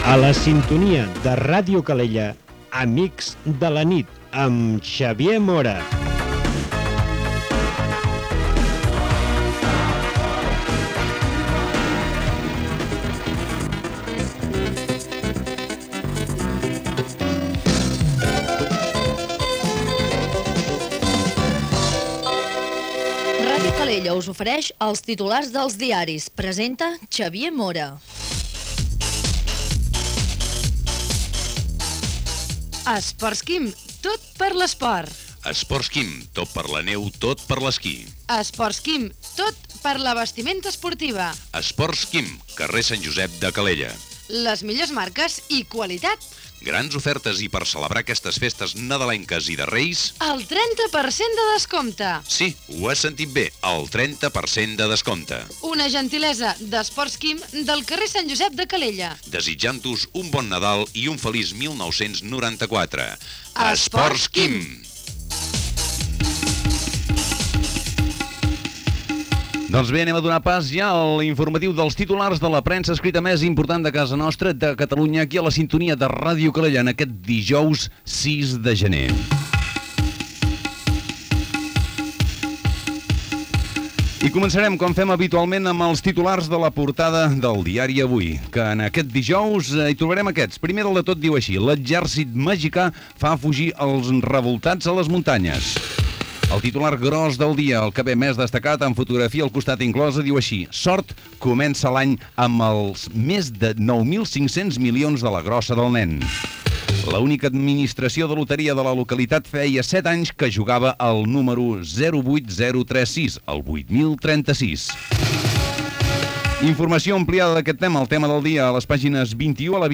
Indicatiu de programa, publicitat, data i presentació dels titulars dels diaris.
Info-entreteniment
FM
Era la nit de Reis.